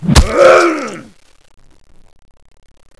corpse_attack2.wav